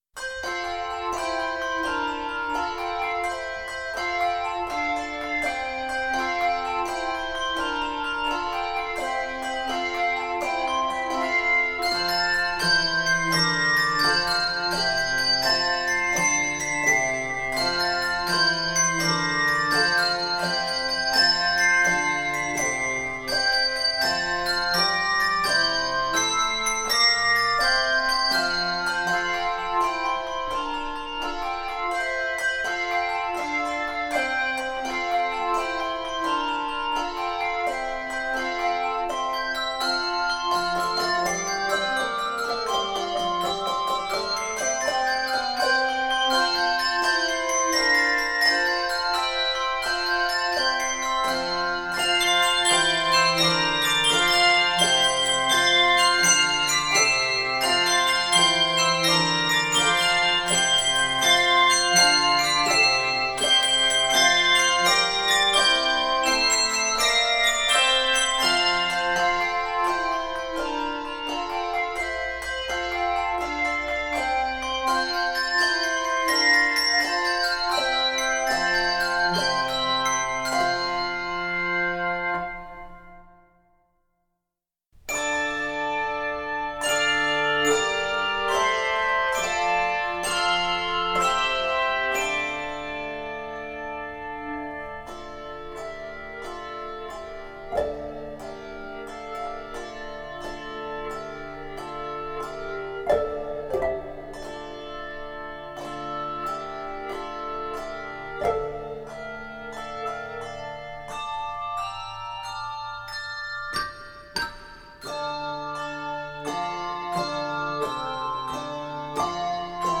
Reformation hymns
3 or 5 octave handbells
is 56 measures in the key of F Major.